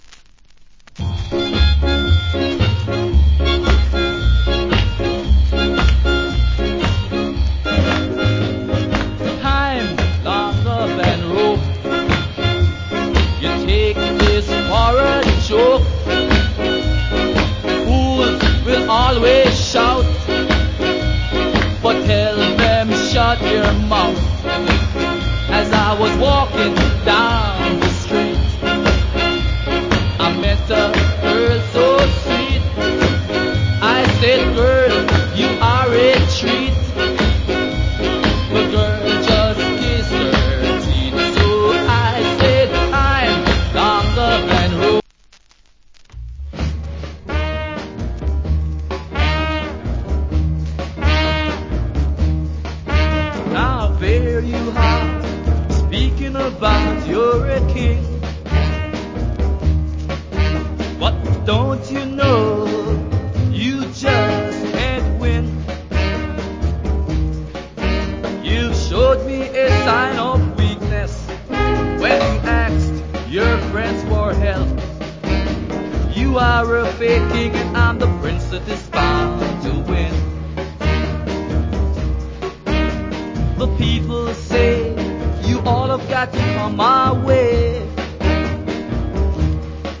Good Ska Vocal.